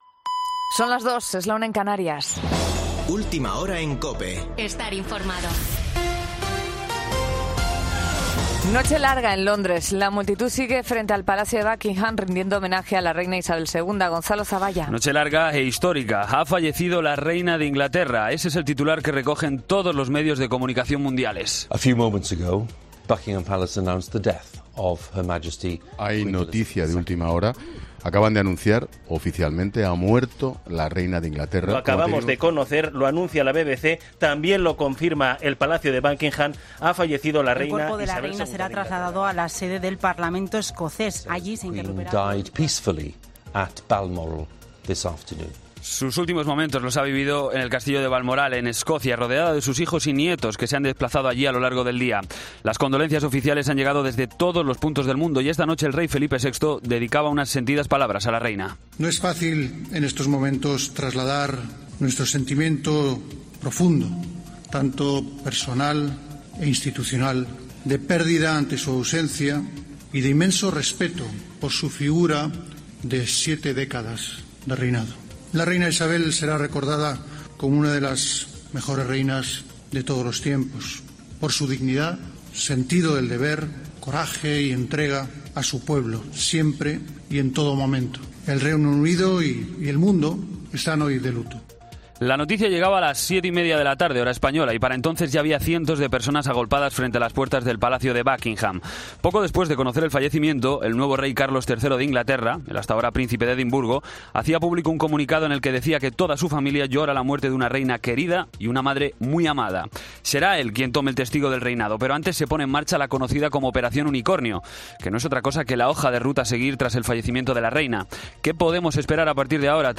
Boletín de noticias COPE del 9 de septiembre a las 02:00 horas